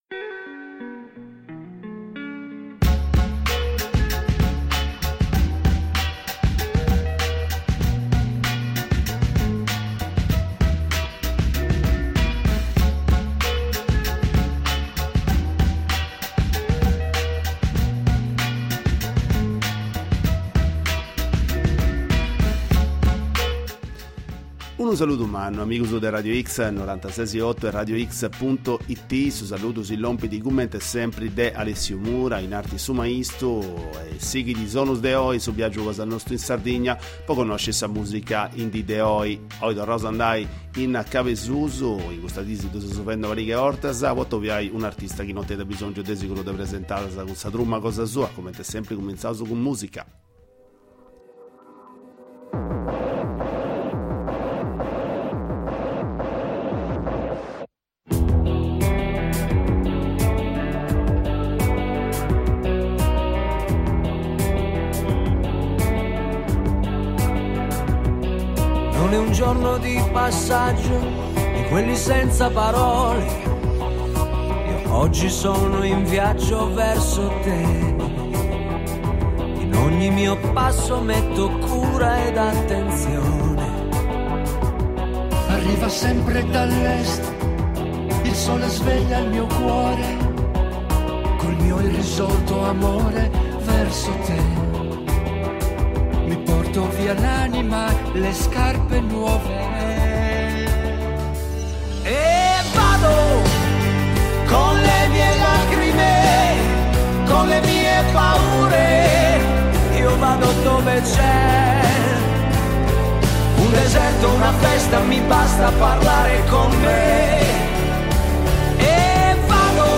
Il loro ultimo disco, suonare assieme da tanti anni, la sperimentazione e la tradizione, il 2020 ed il 2021, aspettando di poter suonare di nuovo dal vivo. Ne abbiamo parlato con Gino Marielli dei Tazenda, nome storico della musica prodotta in Sardegna.